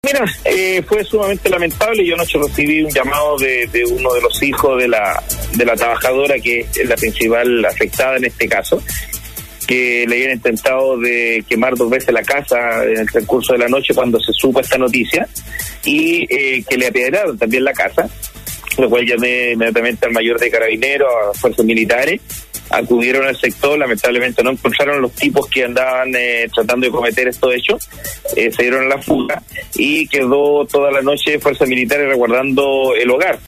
Al respecto, el alcalde de Vallenar, Cristian Tapia, en contacto con Nostálgica, relató lo ocurrido: